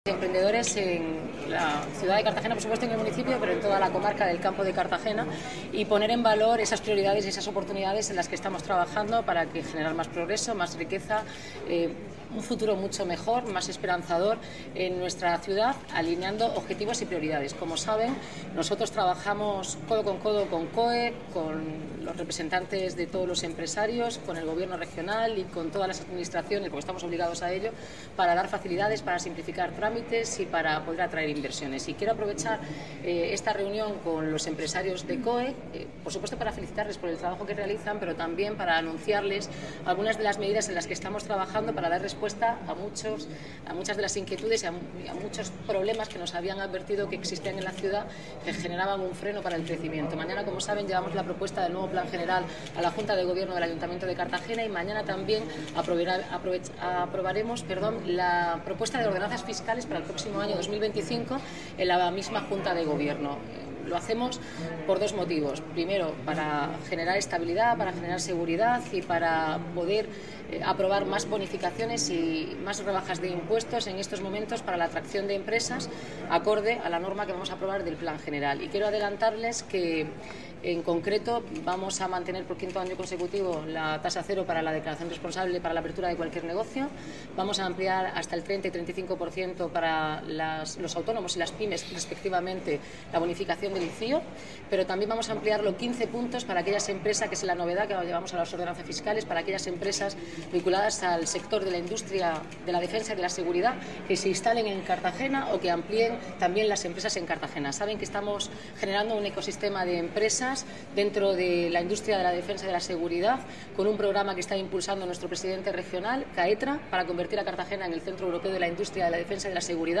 Enlace a Declaraciones de la alcaldesa Noelia Arroyo